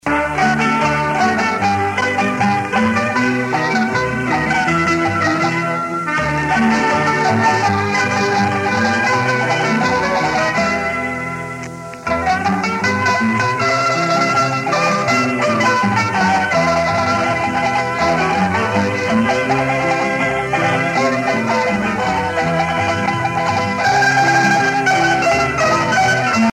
gestuel : danse
Pièce musicale éditée